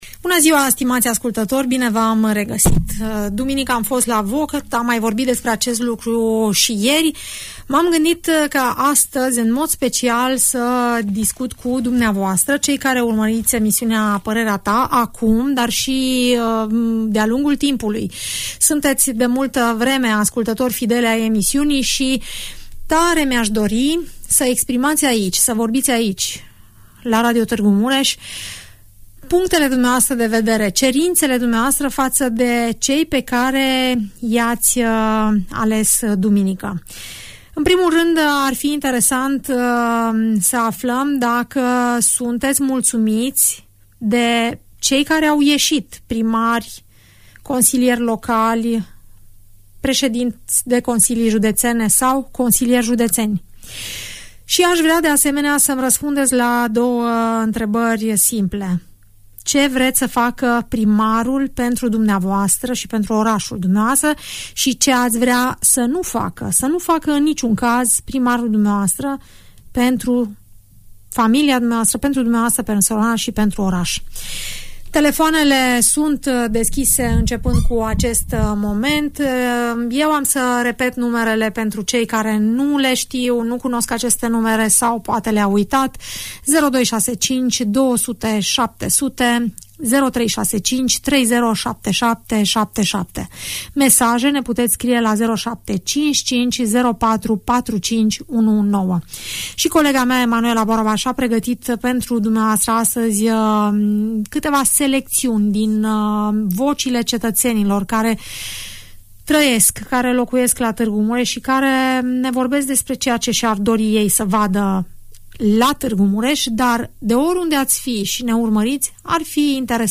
Ascultătorii Radio Tg Mureș, vorbesc în emisiunea „Părerea ta”, despre așteptările pe care le au de la cei pe care i-au votat duminică.